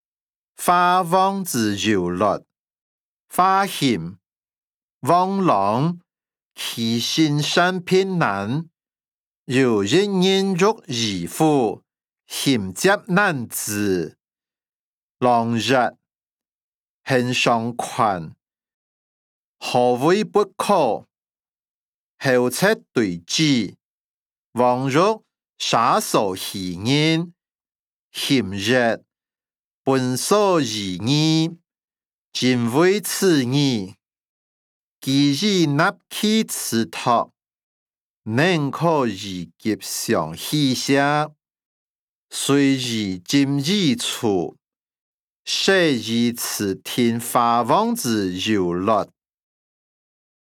小說-華、王之優劣音檔(饒平腔)